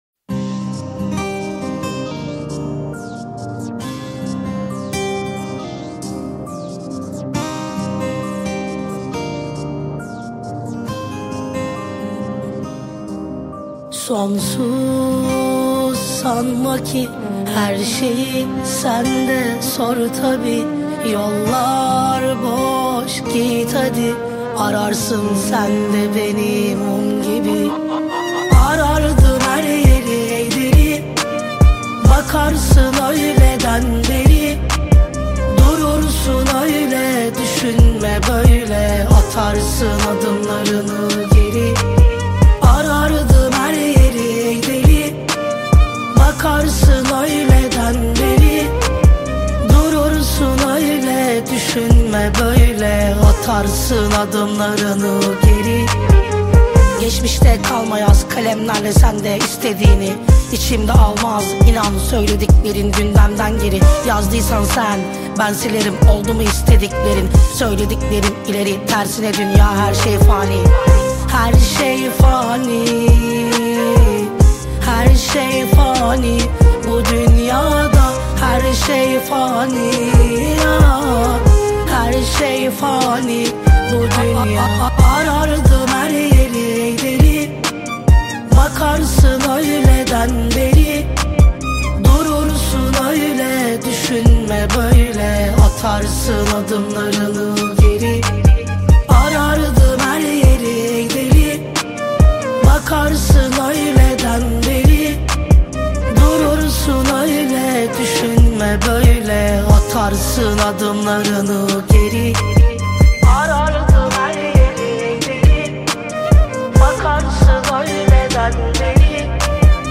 Жанр: Турецкие песни